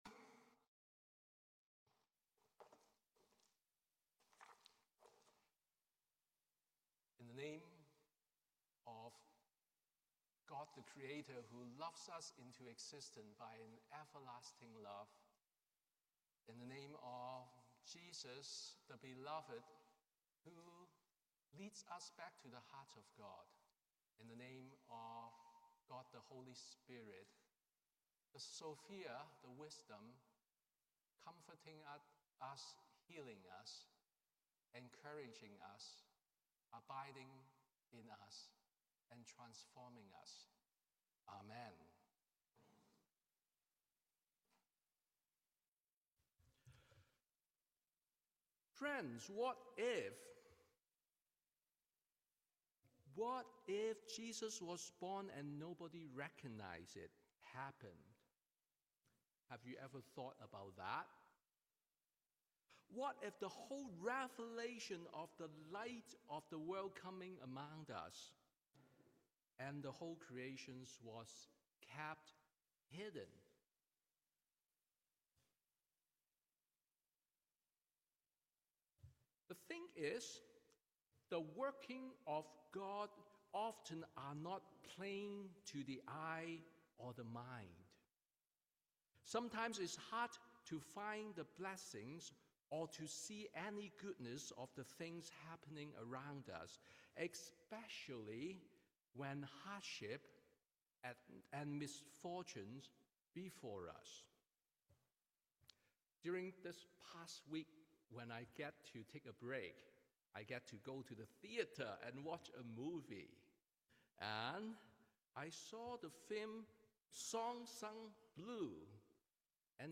Sermon on the Epiphany of the Lord